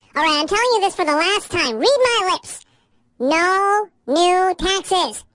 描述：一个人声线的记录和处理，就像一个卡通花栗鼠说的一样。 录音是在Zoom H4n上进行的。使用MOTU Digital Performer中的Spectral Effects进行操纵